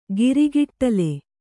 ♪ girigiṭṭale